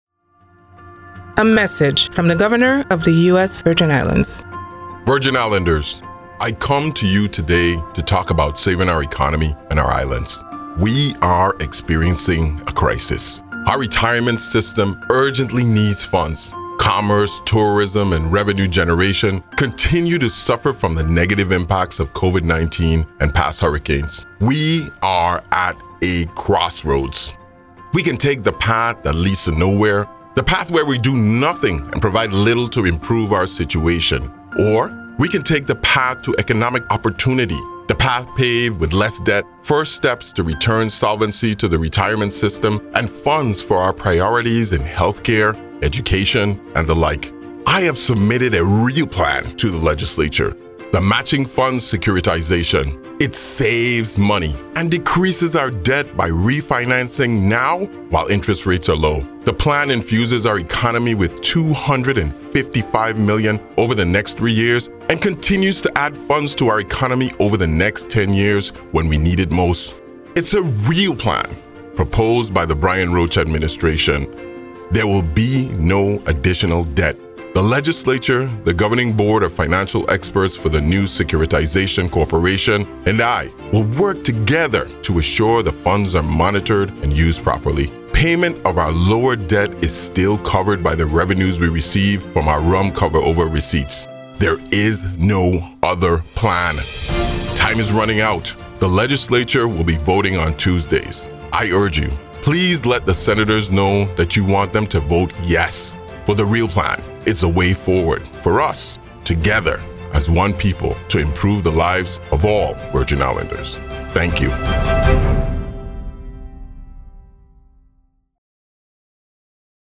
Listen to Governor Albert Bryan Jr. discuss The Real Plan